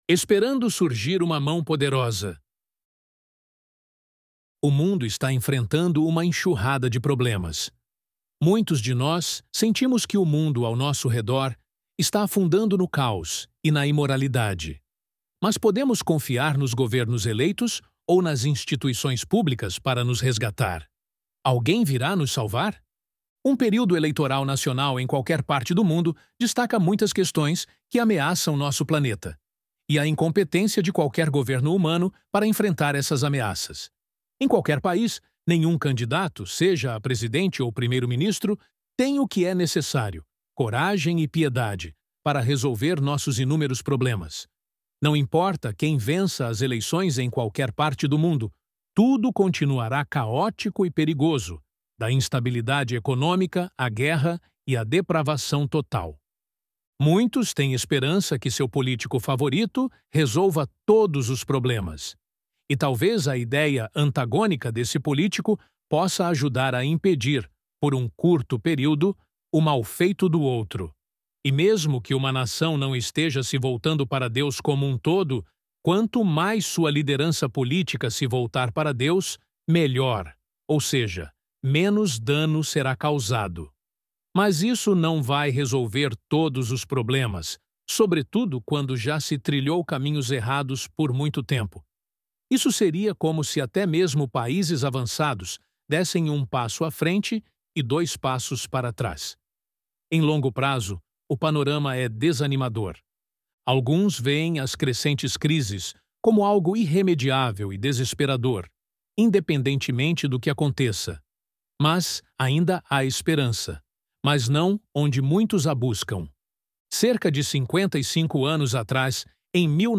ElevenLabs_Esperando_Surgir_Uma_Mão_Poderosa_0.mp3